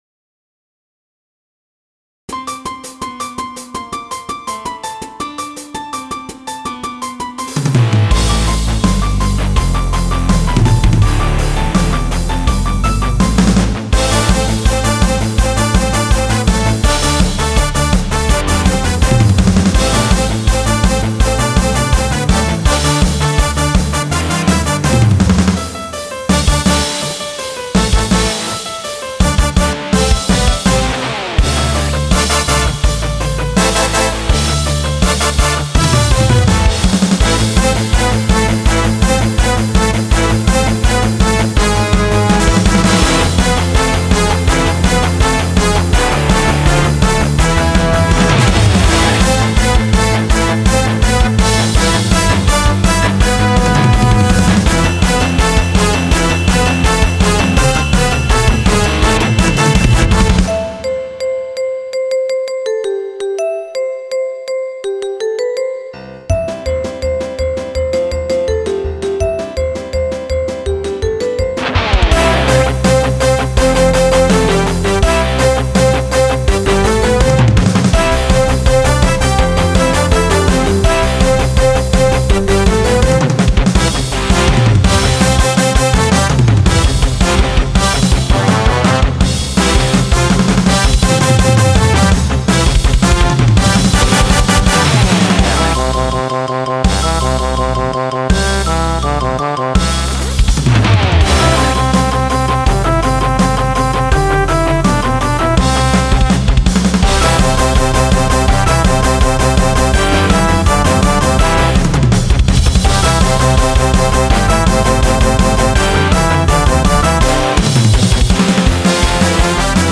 הנה משהו חמוד זה מחרוזת אקוסטית שבניתי כמקצב (כלומר, גם הסולו מנוגן מראש) (זה נטו לאווירה…
הערה:-היות שאתה מייצא כאן שמע לפורום ולא לייב, הבראסים קצת כואבים)